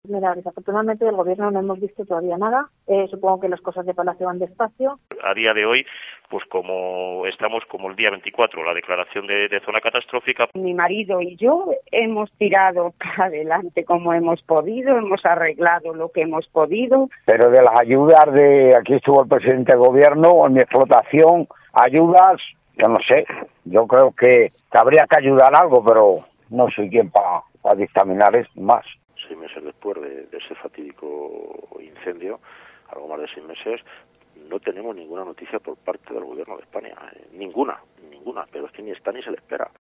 Tren de voces afectados incendio de La Paramera: siguen sin llegar las ayudas del Gobierno Central